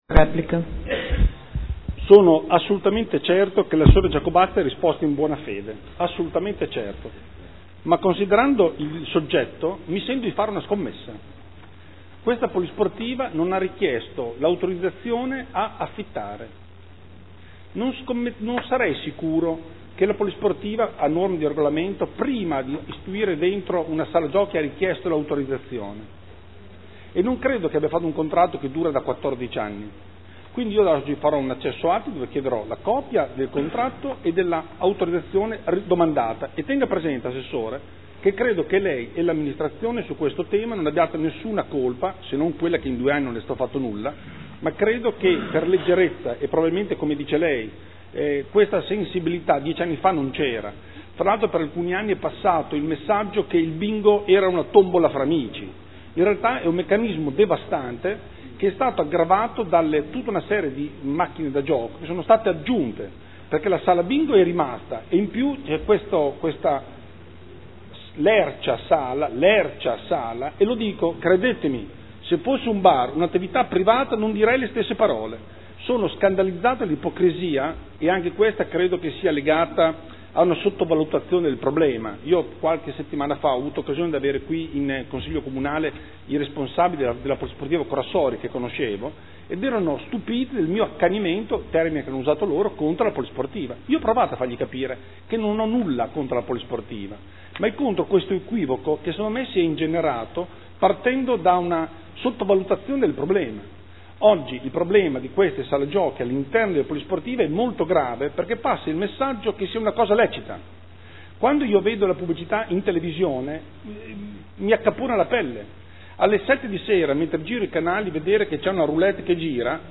Seduta del 21/05/2015 Replica a risposta assessore Giacobazzi.